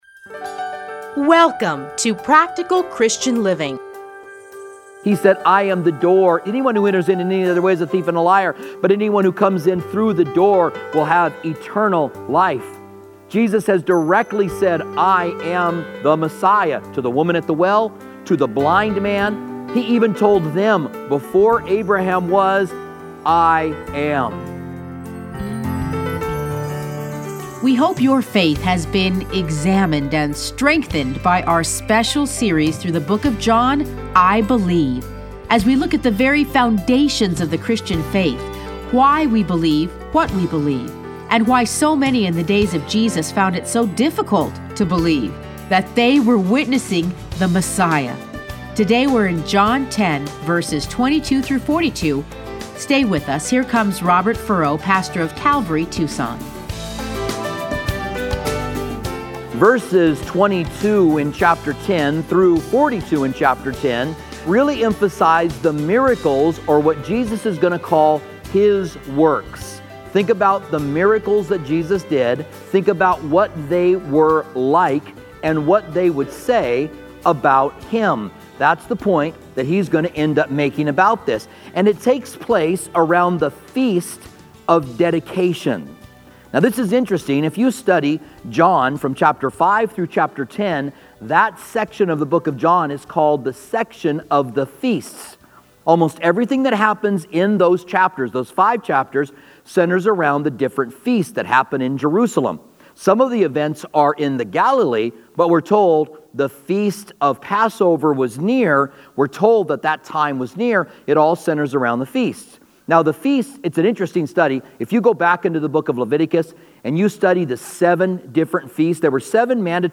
Listen to a teaching from John 10:22-42.